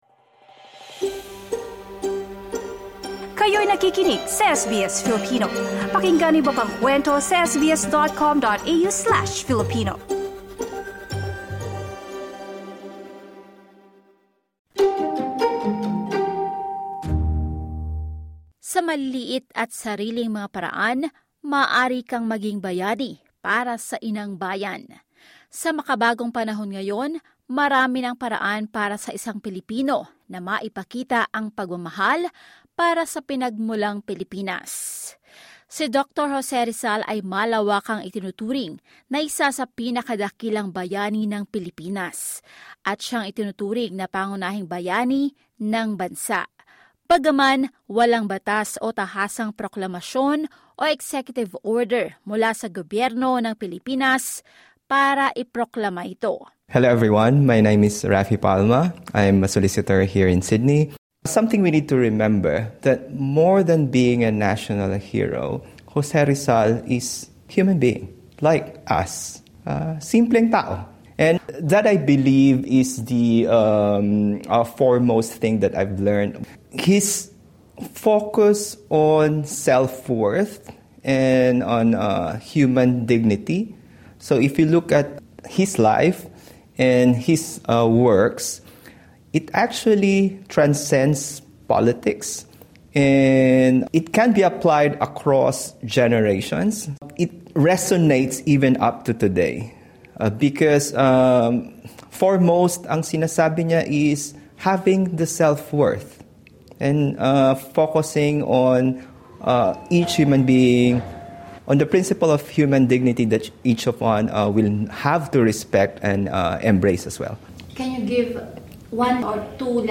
Filipino Australian actors from the play 'Parting at Calamba' share what they learn from the teachings of Dr Jose Rizal and how one can be a hero in this modern time.